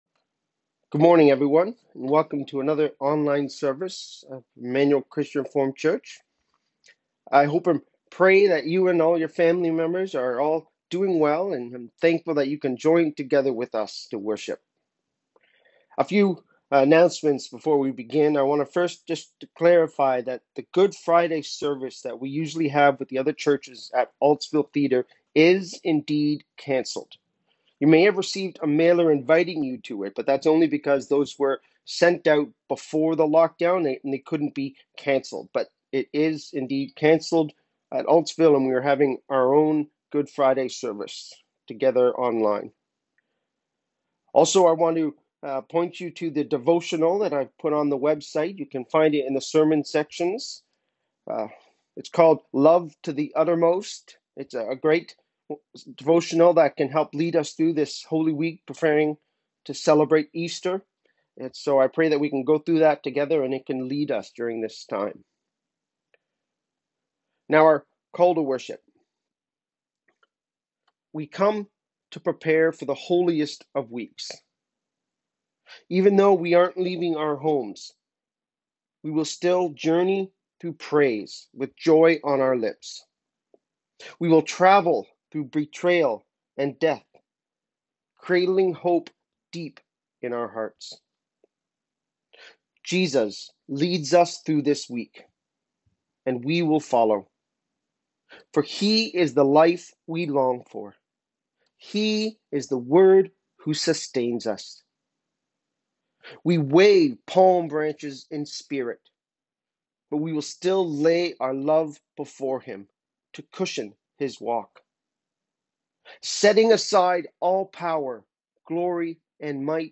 04/04/2020 – Lent Sermon 4